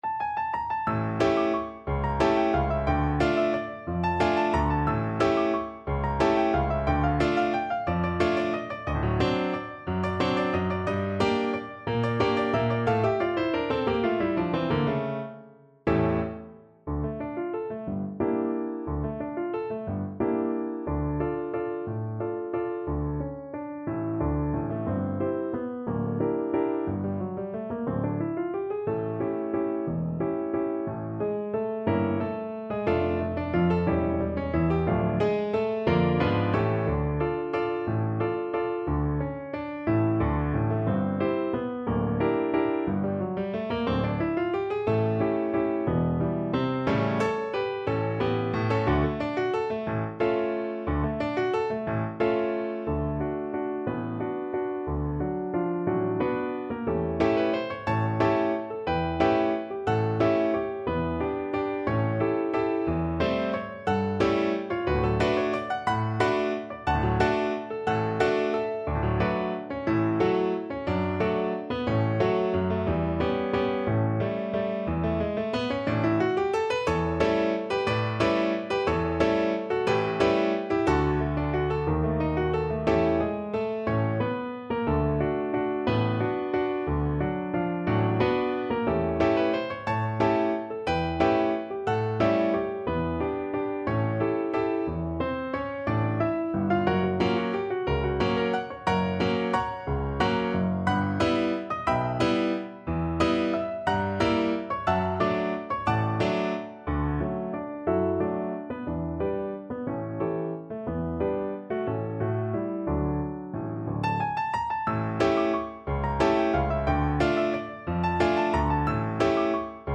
3/4 (View more 3/4 Music)
Allegro movido =180 (View more music marked Allegro)
Mexican